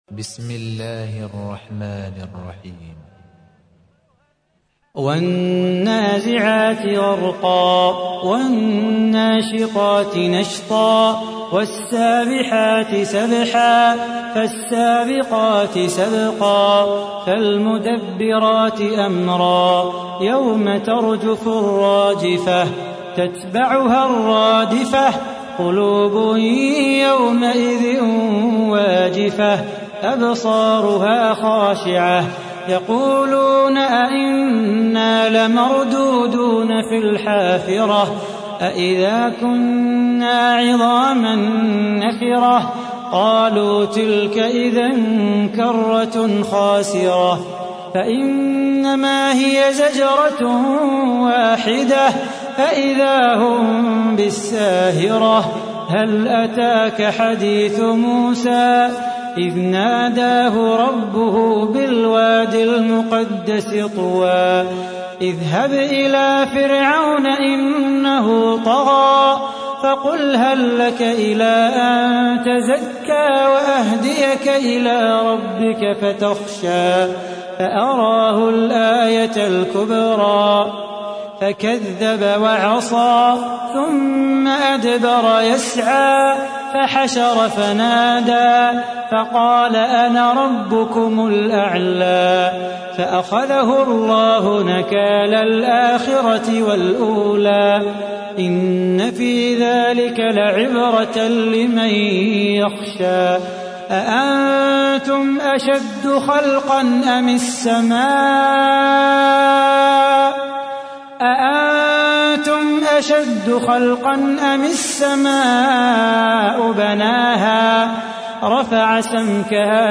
تحميل : 79. سورة النازعات / القارئ صلاح بو خاطر / القرآن الكريم / موقع يا حسين